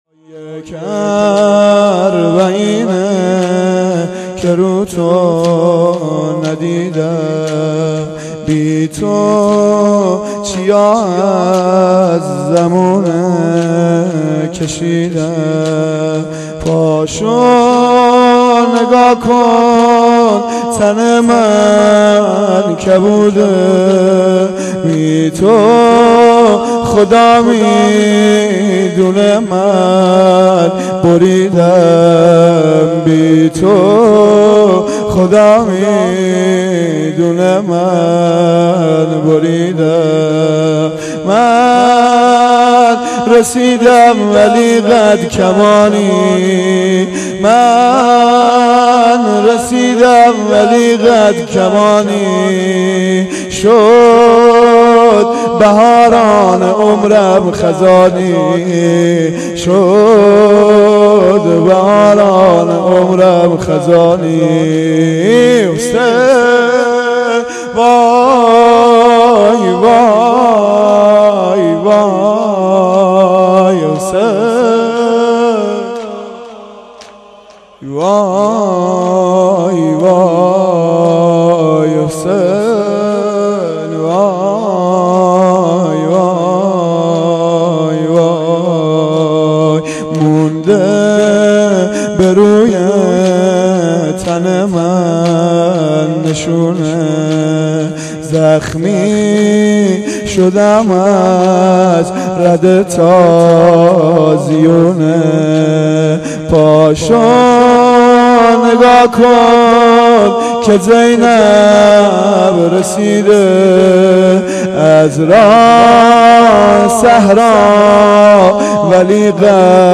اربعین-زمینه.mp3